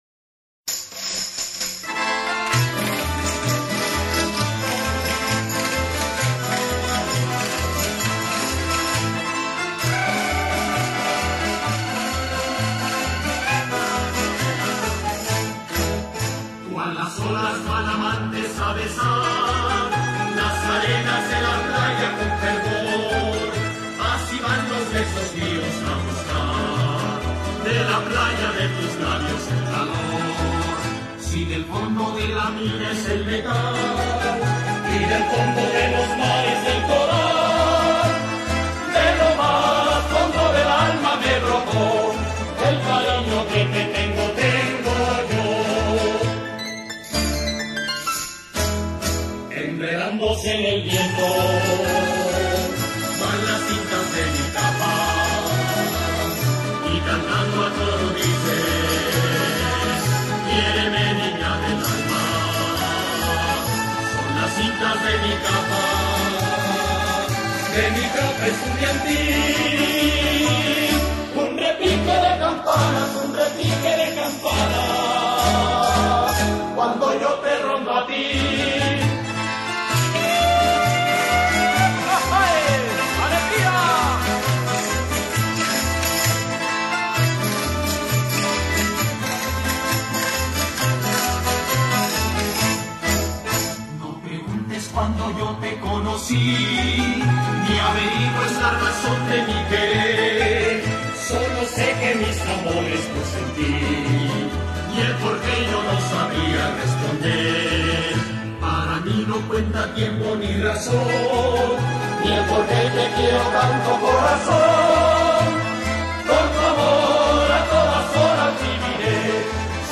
Старинная песня испанских студентов